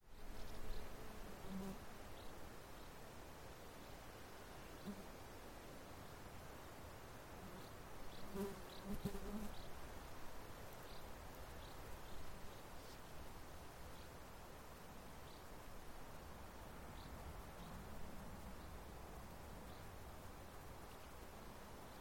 Звук: спокойный летний луг с жужжанием насекомых